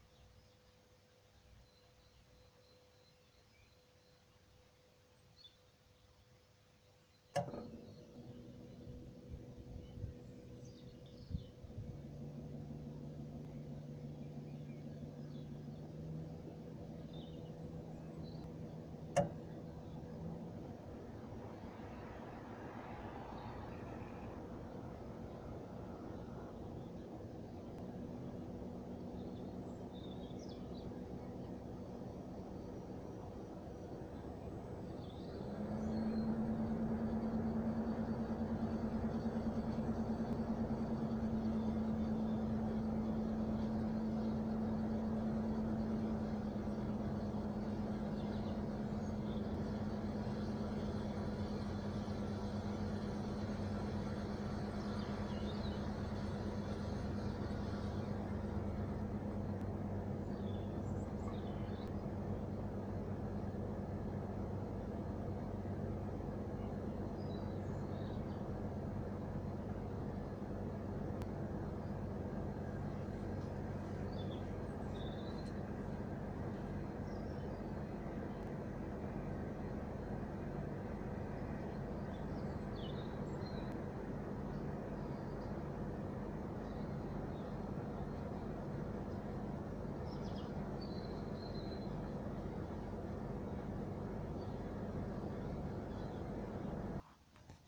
forum consulter ce sujet Bruit ronflement unité extérieure Mitsubishi MXZ-3D54VA sur le forum Climatisation
Il a démonté l'hélice du compresseur et à passer du w40 sur l'axe, mais le bruit est toujours là, il se produit au démarrage du compresseur, lorsque l'hélice prend de la vitesse.
J'ai fait un enregistrement avec mon téléphone, voir en début d'enregistrement et en fin d'enregistrement.
bruit-exterieure-mitsubishi.mp3